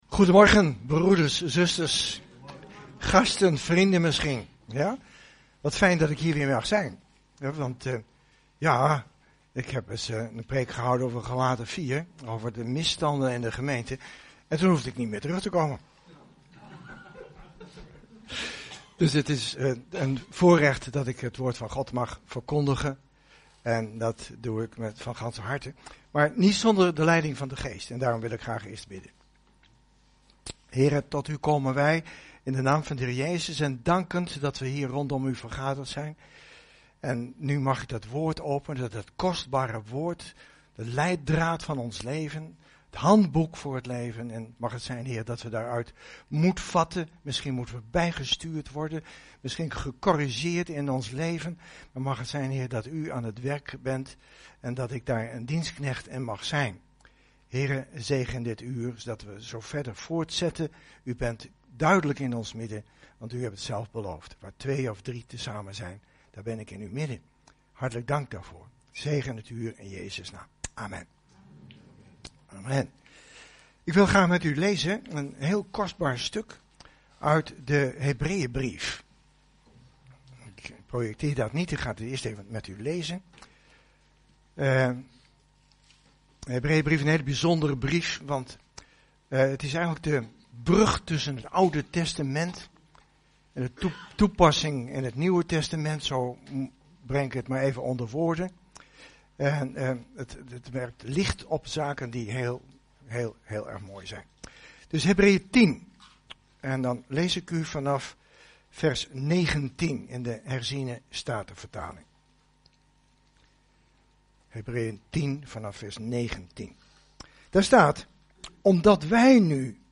Over deze preek